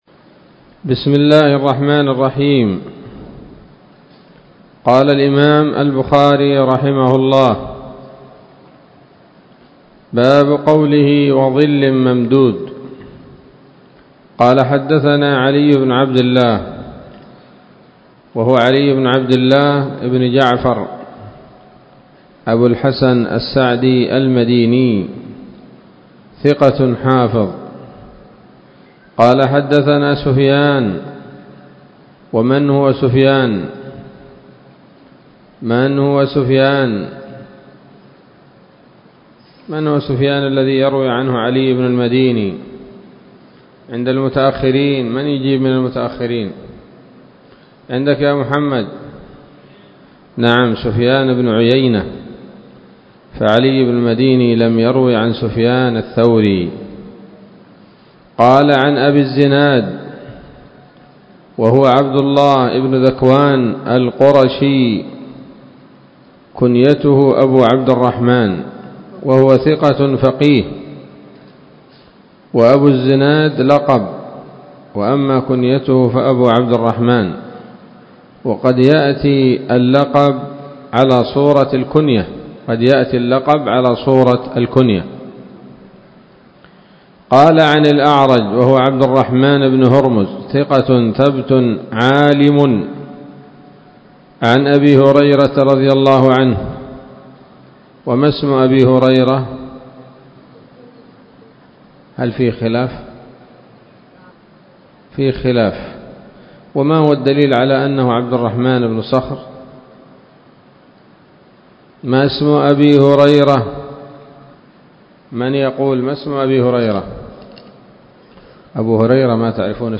الدرس الحادي والخمسون بعد المائتين من كتاب التفسير من صحيح الإمام البخاري